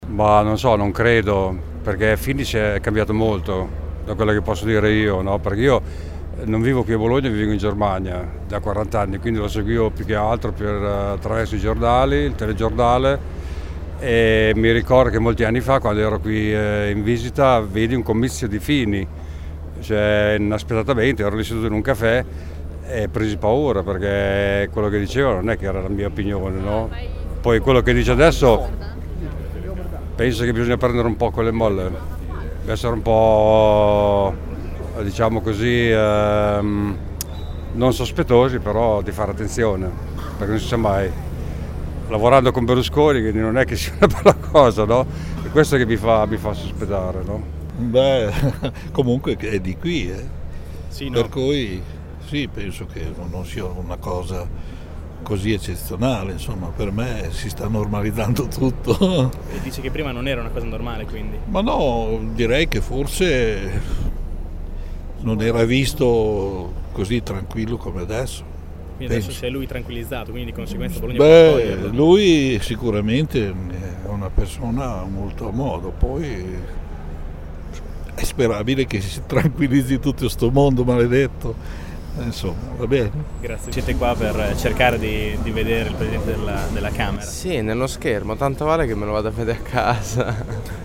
Abbiamo raccolto alcune opinioni dei cittadini che hanno assistito all’incontro: dalle loro voci non sembra che sia accaduto granchè, anzi.
Tra i cittadini prima dell’incontro: